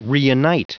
Prononciation du mot reunite en anglais (fichier audio)
Prononciation du mot : reunite